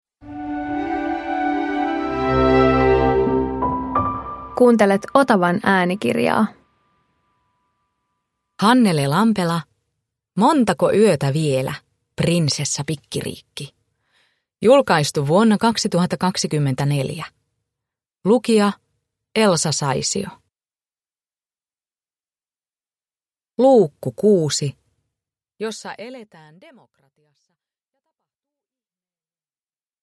Montako yötä vielä, Prinsessa Pikkiriikki 6 – Ljudbok
Uppläsare: Elsa Saisio